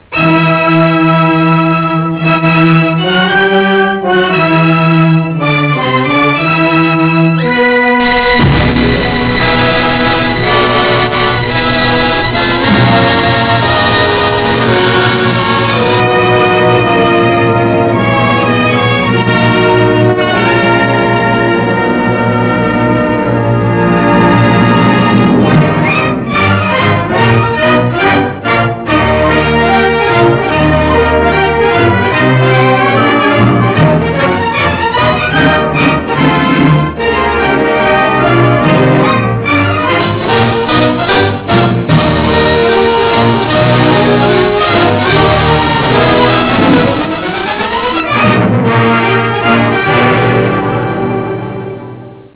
Original track music: